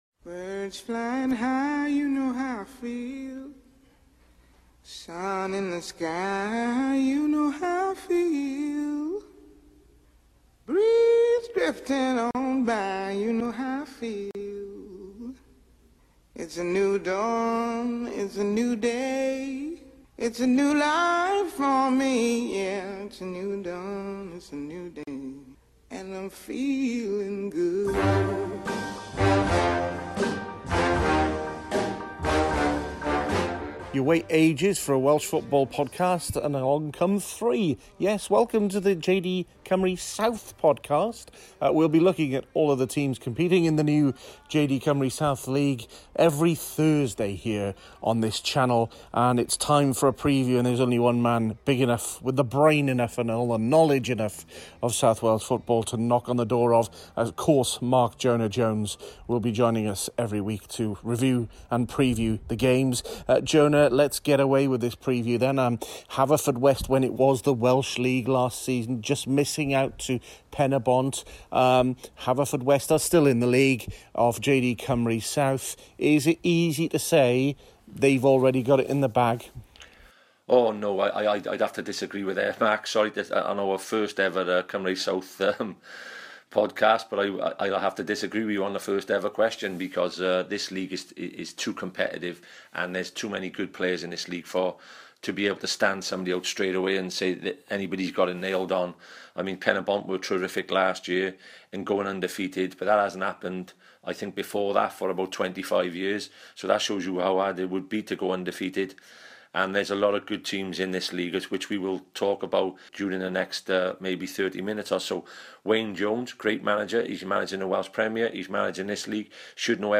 There's also manager interviews from Cambrian and Clydach, Cwmamman and Haverfordwest.